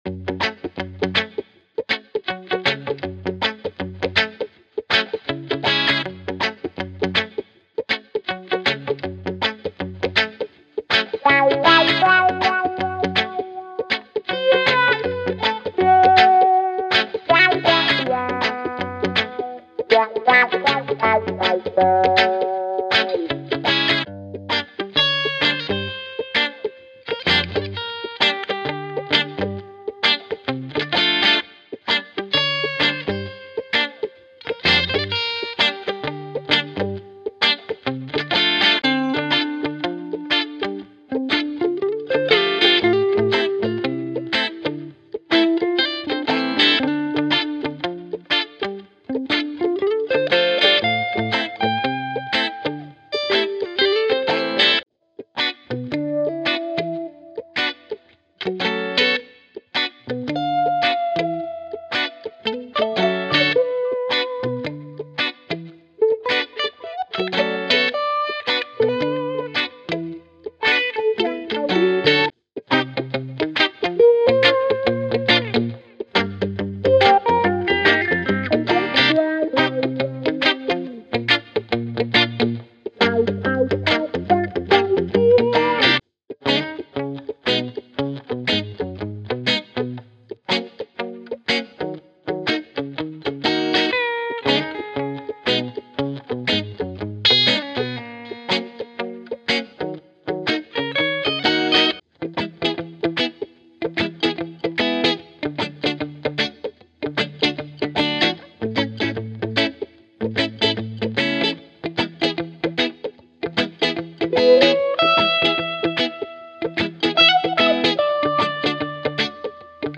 Elevate your tracks with the authentic sound of reggae guitar!
10 Guitar Ensemble Loops: Rich, layered guitar sections that provide a full, immersive sound, perfect for building the foundation of your track.
10 Rhythm Guitar Loops: Solid, groove-centric rhythms to drive your tracks forward with that classic reggae feel.
20 Muted Rhythm Guitar Loops: Tight, percussive strumming patterns that add subtle movement and texture to your music.
10 Lead Guitar Loops: Expressive lead lines that cut through the mix, adding melodic interest and character.
10 Wah Wah Guitar Rhythm Loops: Funky, dynamic wah-wah rhythms that bring a playful, groovy element to your productions.
Tempo Range: Loops range from 120 to 180 BPM, providing versatility for various reggae sub-genres and creative projects.
High-Quality Sound: Professionally recorded and produced to ensure pristine audio quality.
Reggae-Guitars-Vol-2-demo.mp3